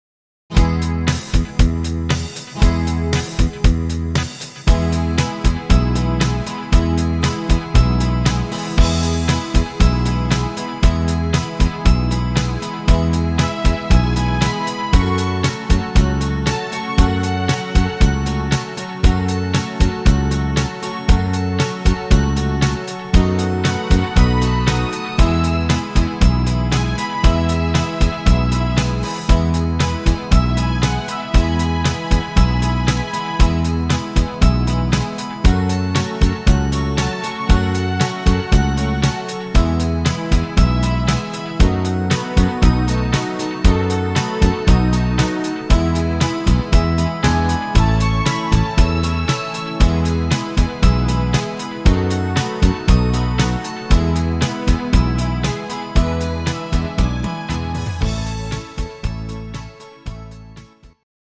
Evergreen aus Frankreich